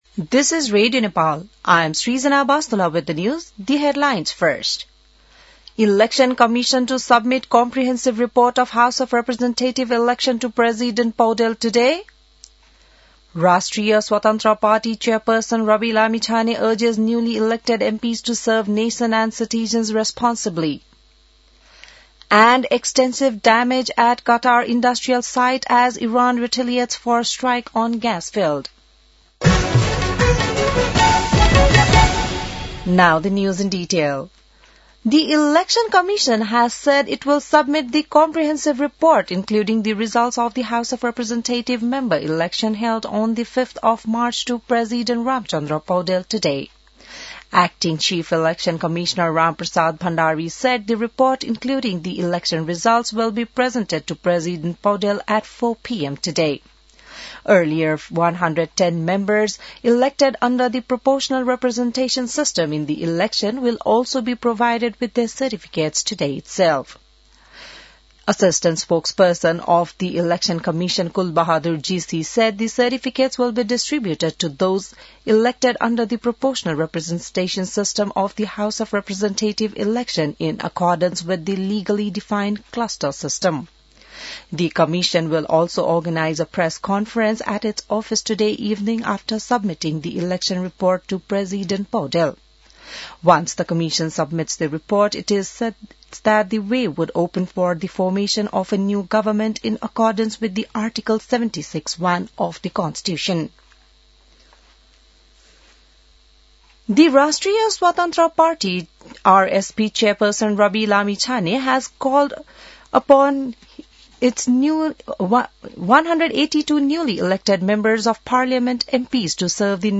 An online outlet of Nepal's national radio broadcaster
बिहान ८ बजेको अङ्ग्रेजी समाचार : ५ चैत , २०८२